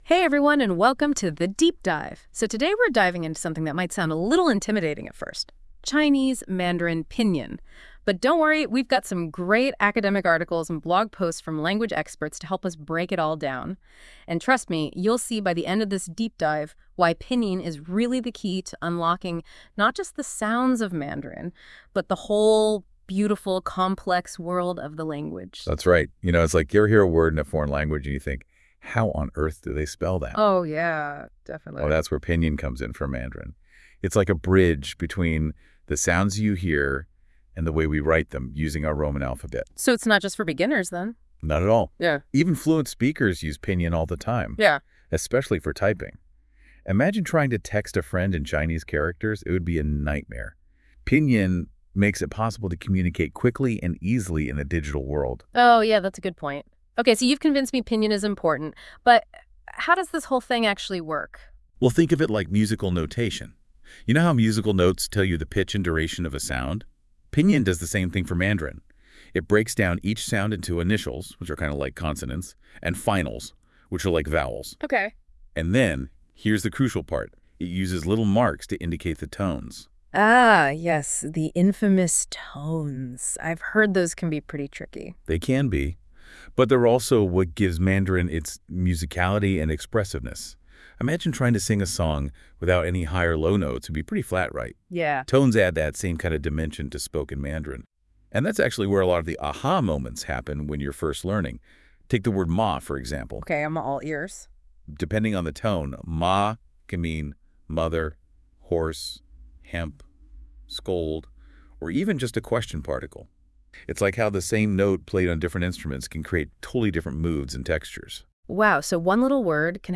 Master Chinese Pinyin: Audio Guide for English Speakers